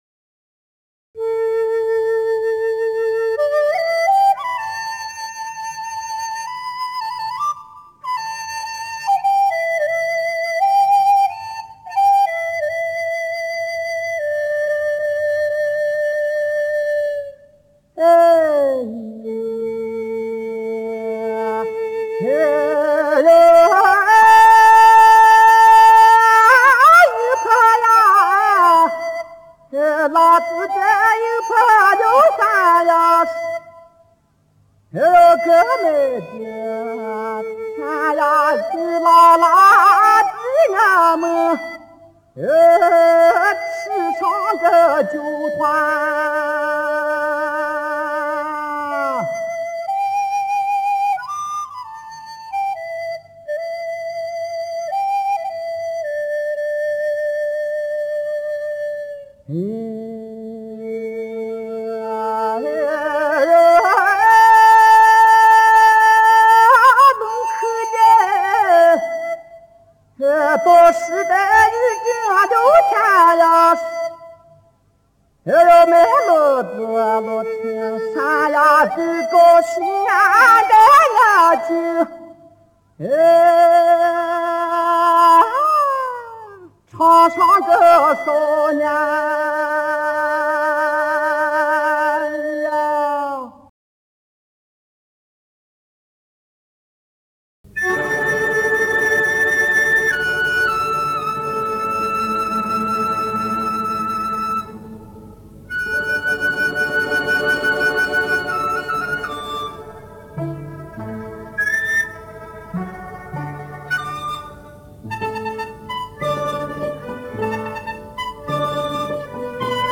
1964年录音